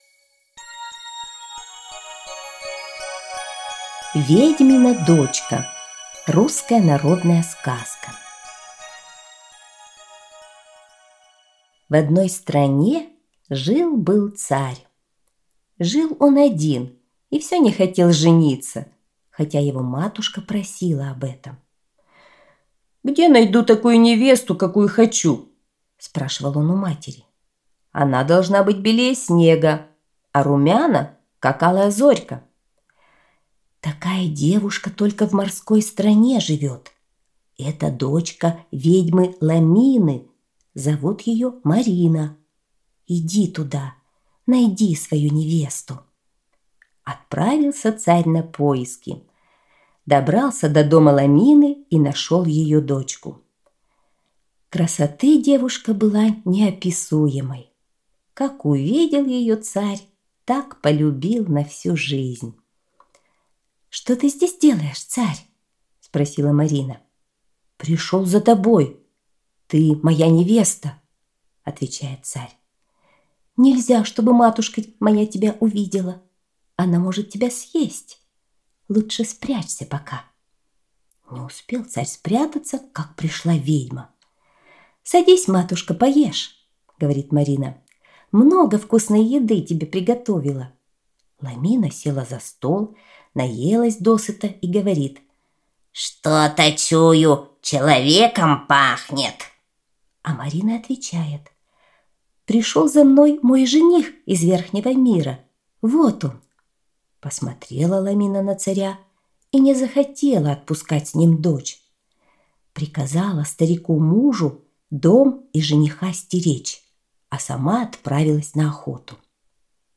Ведьмина дочка – русская народная аудиосказка